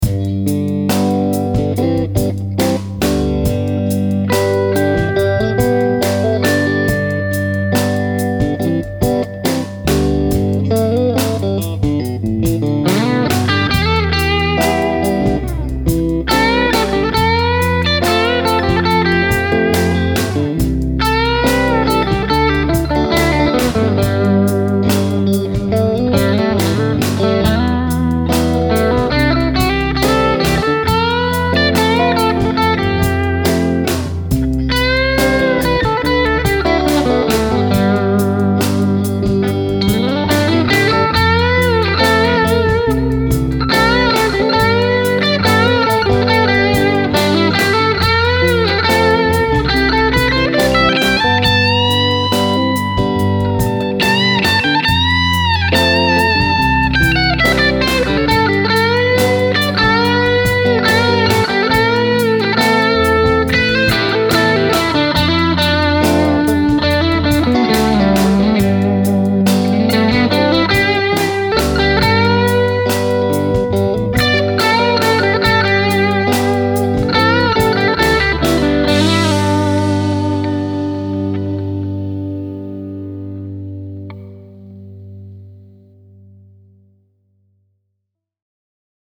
In both clips, I have my Aracom VRX22 set up in its drive channel with the master and and volume knobs set to about 1pm each.
With a Strat, these gain positions set it at the edge of breakup, and I have to really dig in to get distortion out of the amp.
This time, I play the first part with the raw Strat, then in the second part add the Timmy to finish the song: